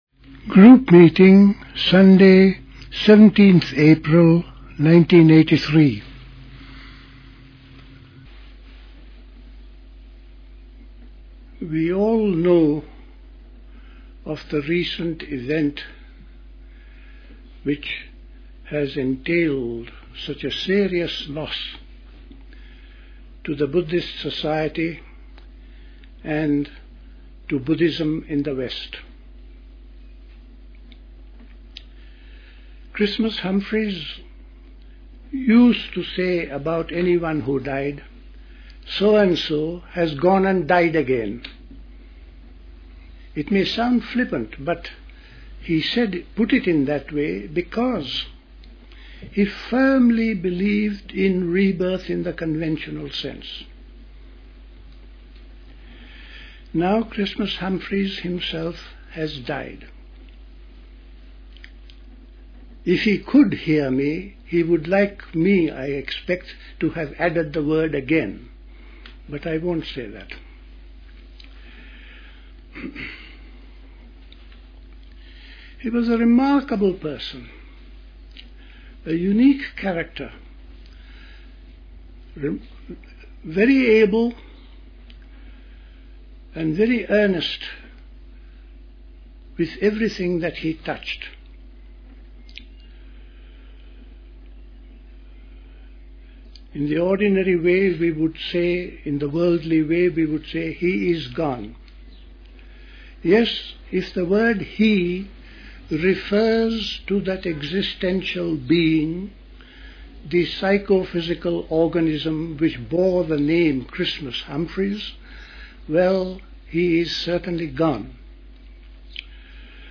A talk
at Dilkusha, Forest Hill, London